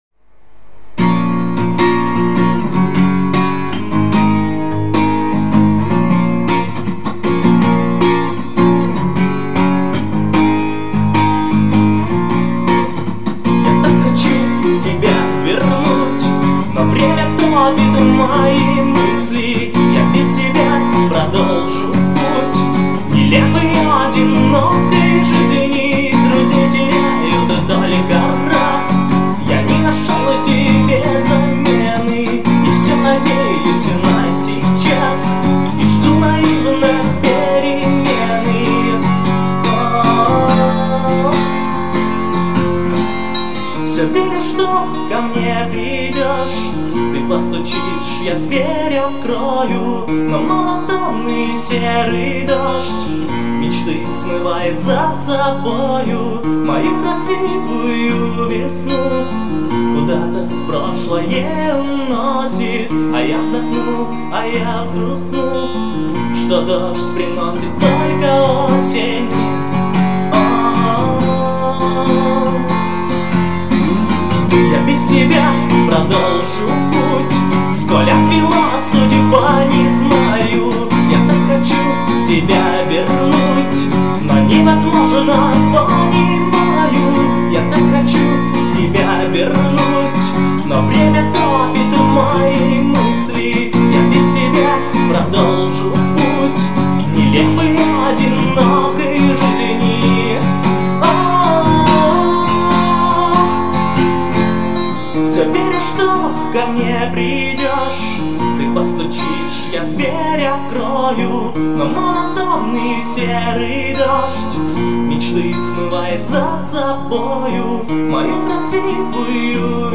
Армейская_песня_под_гитару_-_я_так_хочу_тебя_вернуть
Armeqskaya_pesnya_pod_gitaru___ya_tak_hochu_tebya_vernutjq.mp3